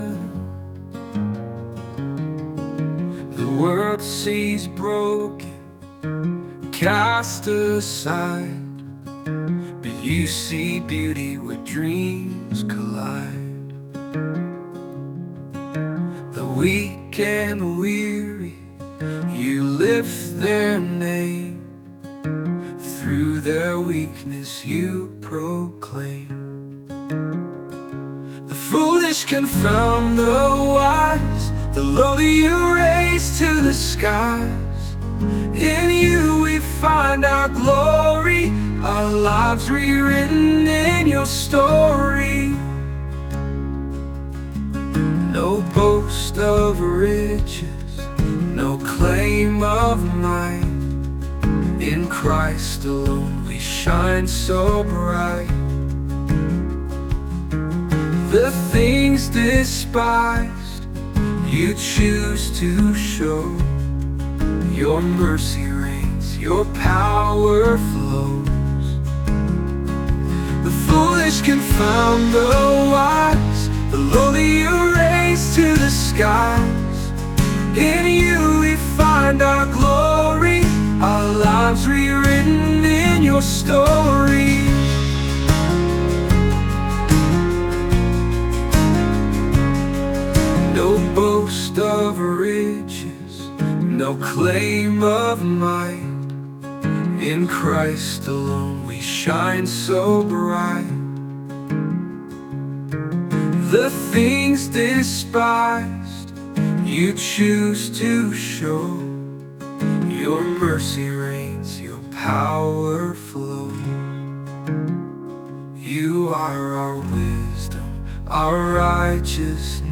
Christian Praise | Christian Worship